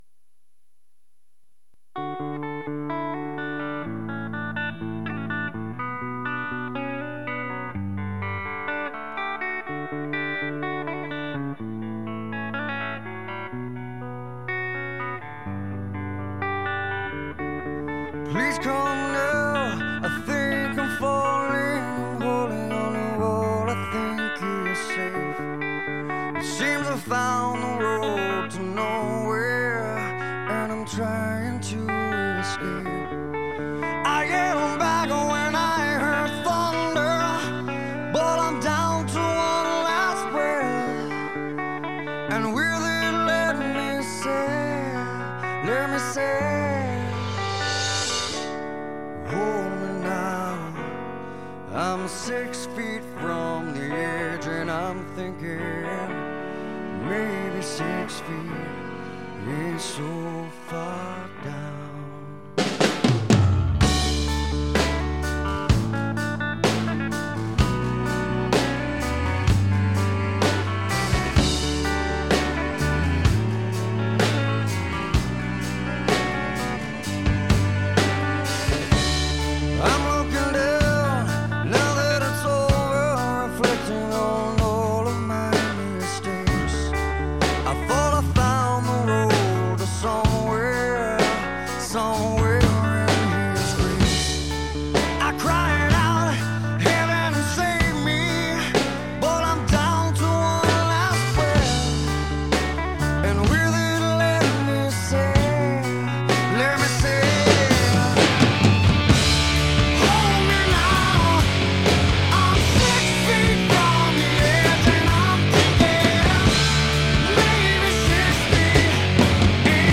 Just played along: